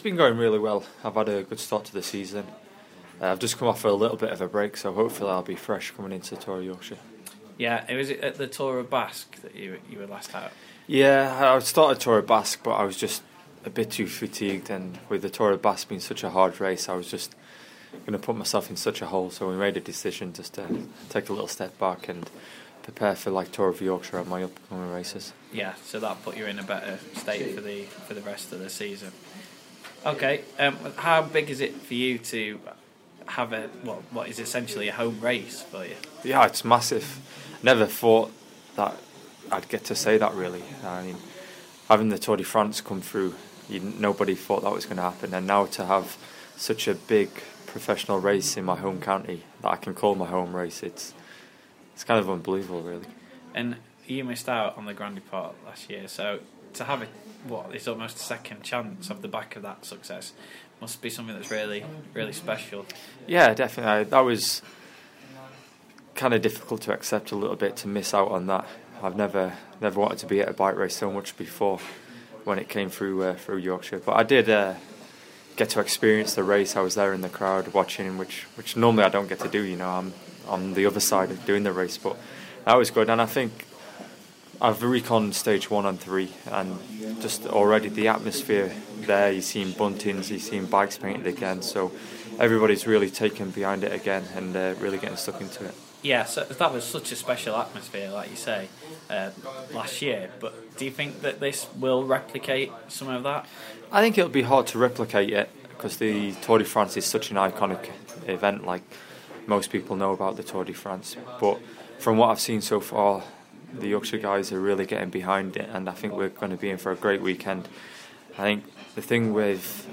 Team Sky Cyclist Ben Swift talks to Radio Yorkshire ahead of the Tour de Yorkshire